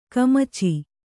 ♪ kamaci